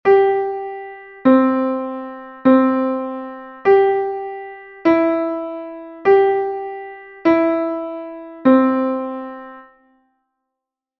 G,C and E note recognition exercise 4
note_recognition_4.mp3